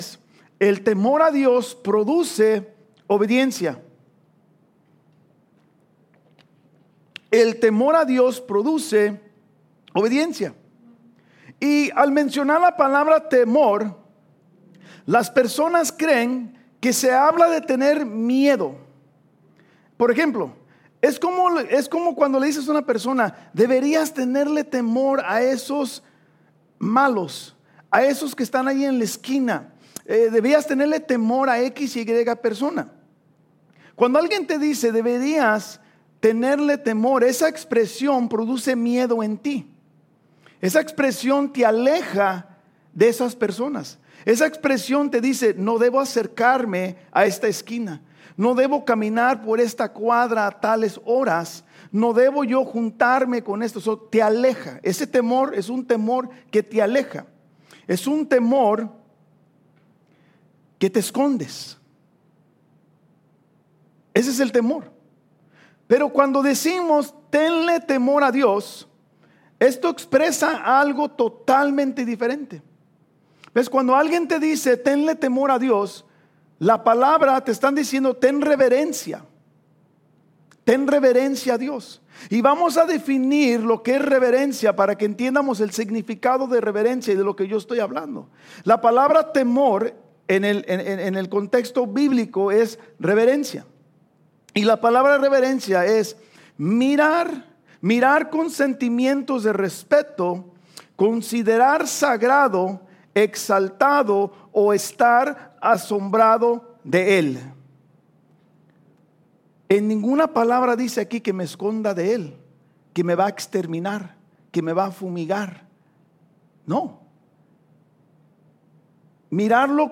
Estudio Biblico | Iglesia Vida Hammond